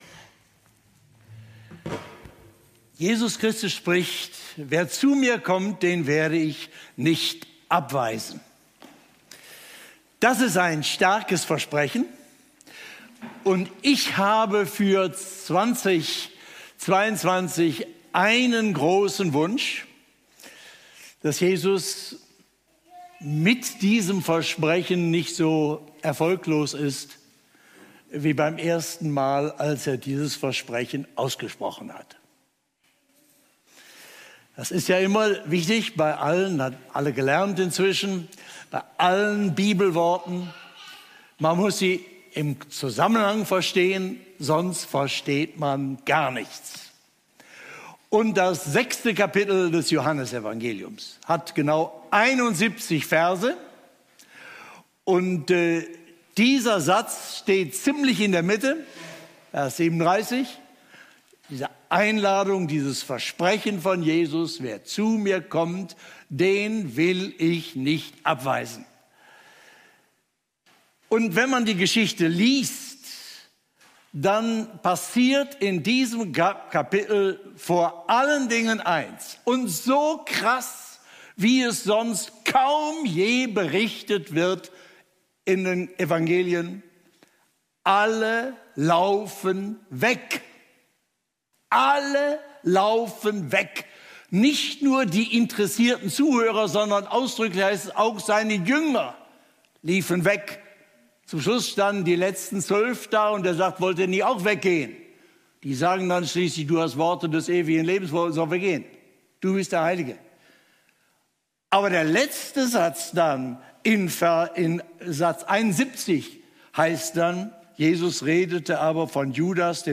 legte die Jahreslosung 2022 (das biblische Leitwort der evangelischen Kirchen) im Gottesdienst der Landeskirchlichen Gemeinschaft Friedenshof, Kassel, am 2. Januar 2022 aus.